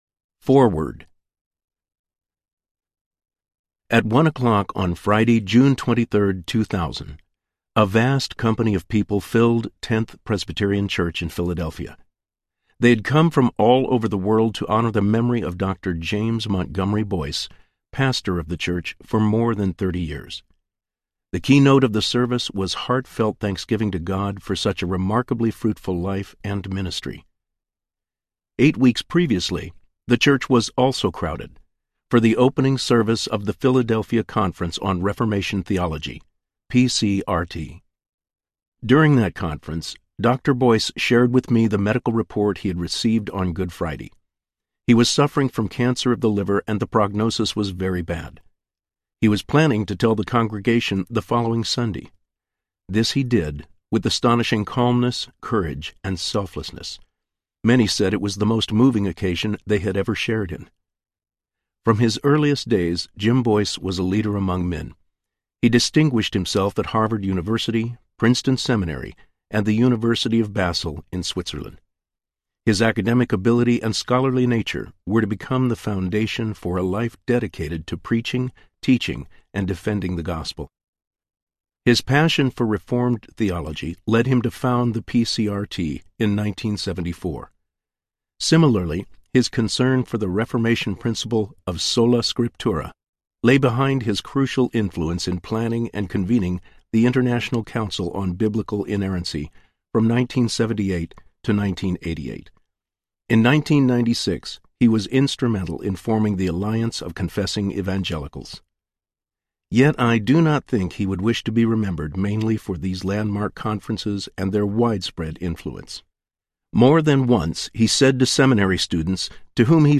Whatever Happened to The Gospel of Grace? Audiobook
Narrator
7.6 Hrs. – Unabridged